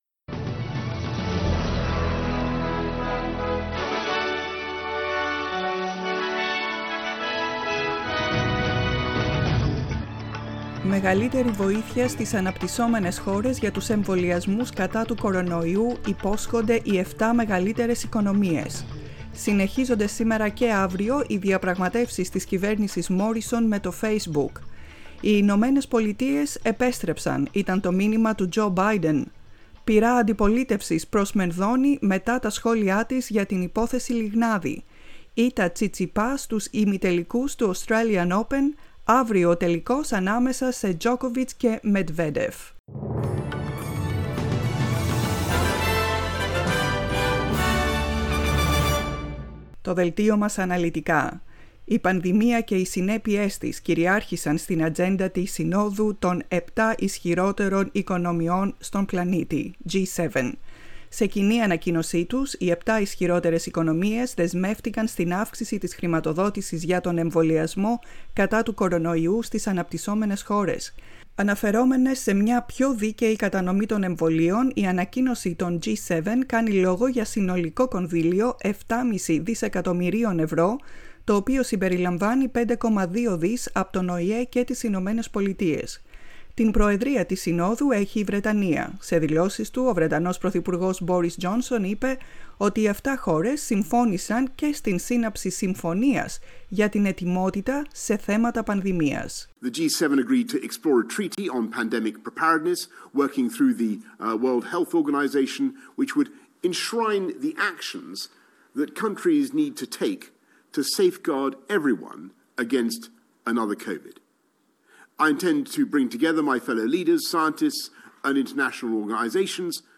News in Greek, 20.02.21
The main bulletin